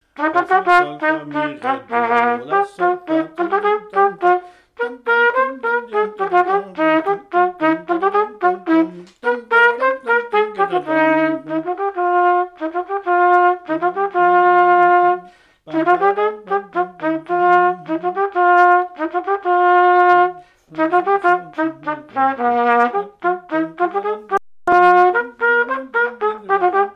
Mémoires et Patrimoines vivants - RaddO est une base de données d'archives iconographiques et sonores.
danse : quadrille
Pièce musicale inédite